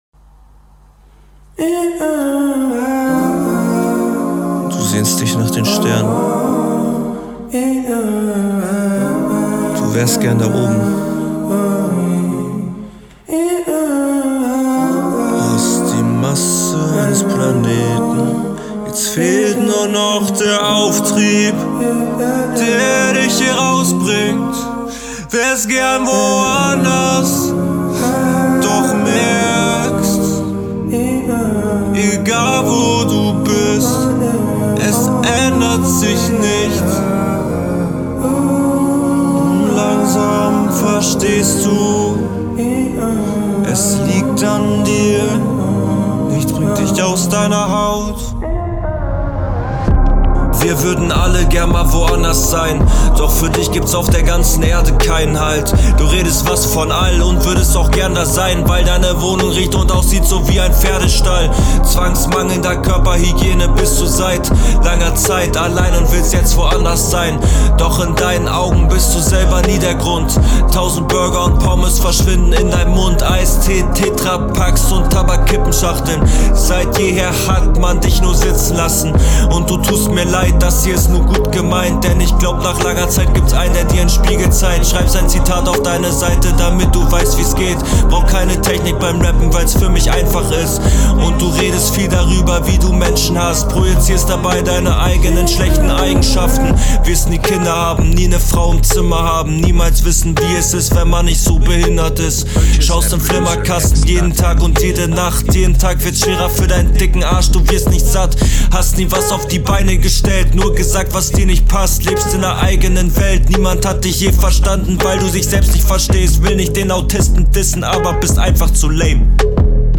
flow kommt echt nice und wirkt alles sehr stimmig, du kommst echt gut auf den …
Interessanter Ansatz zu Battlen in diesem Post Malone artigem Stil, Punches relativ oberflächlich mit.der Konstruierten …